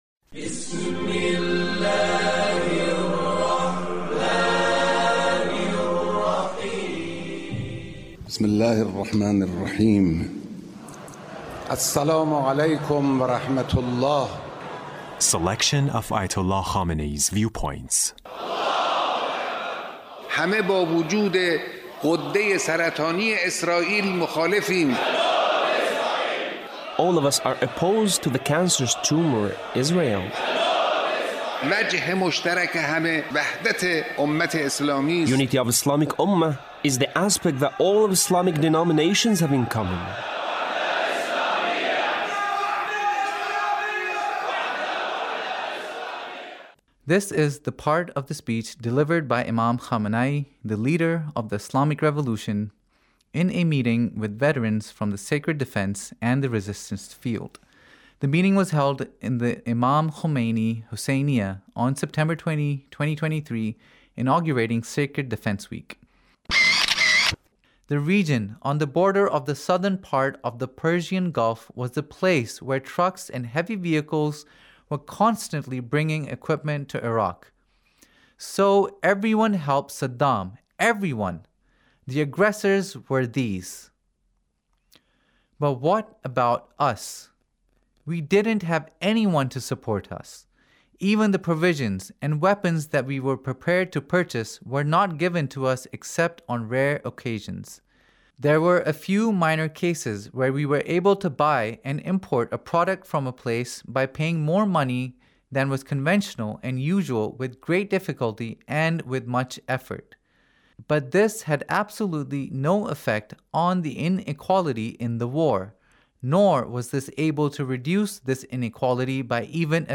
Leader's Speech (1875)
Leader's Speech about Sacred defense